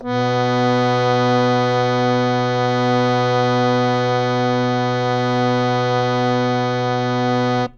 interactive-fretboard / samples / harmonium / B2.wav